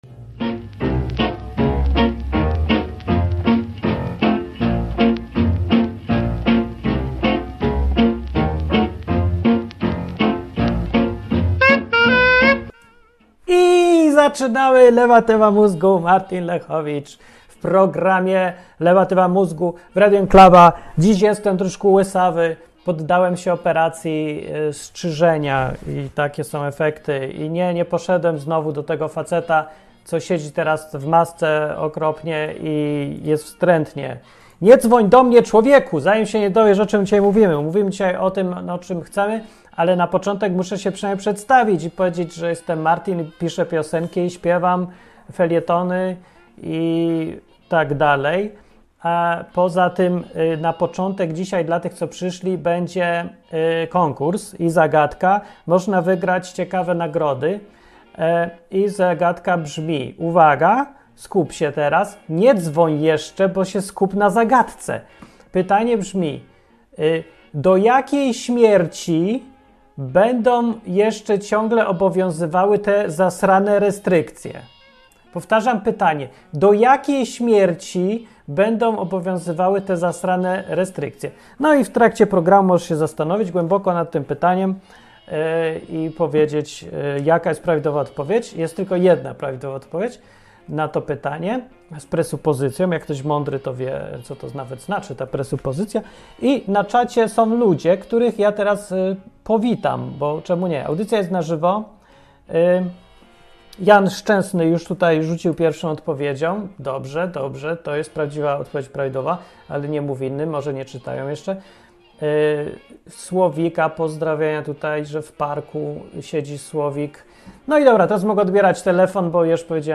Konkurs dla słuchaczy. Ludzie dzwonią i się chwalą. W Szwajcarii państwo płaci nieletnim za strzelanie ostrą animunicją.
Program satyryczny, rozrywkowy i edukacyjny.